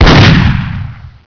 def_ssg_fire.wav